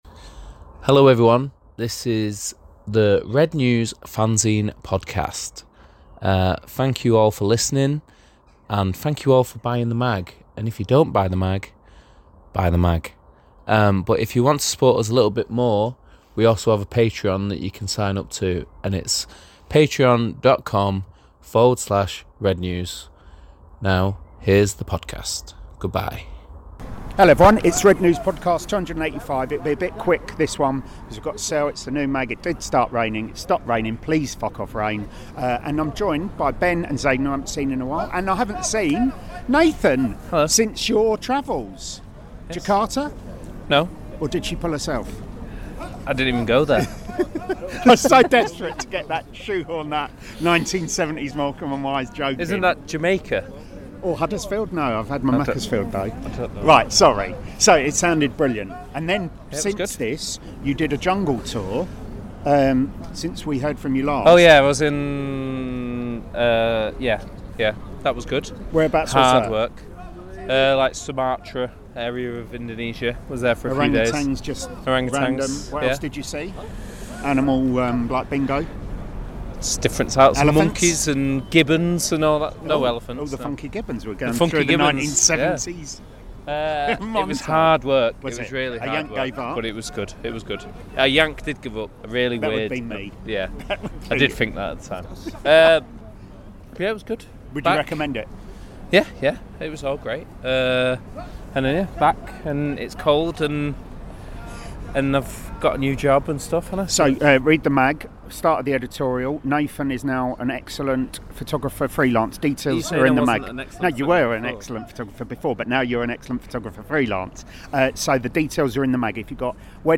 A diabolical defeat to ten men Everton. Pre and post match plus an exclusive segment from our Diogo Dalot interview.